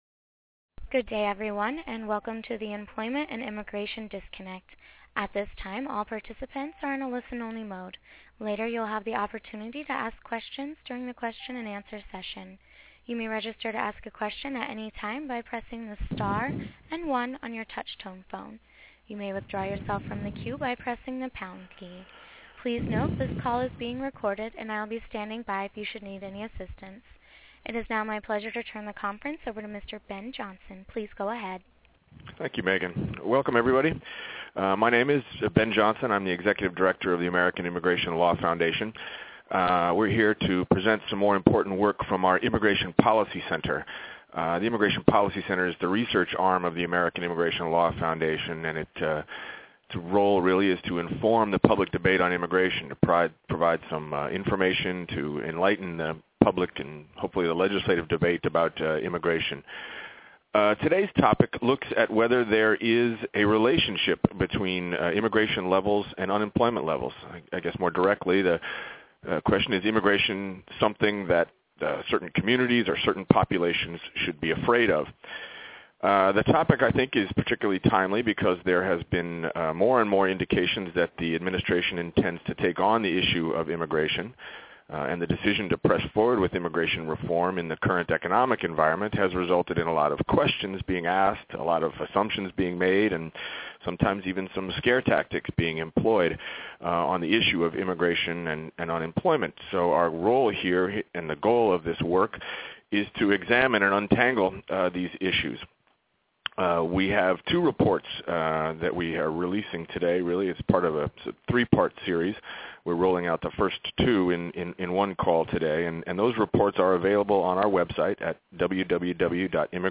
On Tuesday, May 19th at 1p.m. EST, join leading researchers for a telephonic briefing with Q&A to learn more about The Unemployment and Immigration Disconnect.
WHAT: On-the-record, telephonic press briefing with Q&A to discuss recent research on unemployment and immigrants.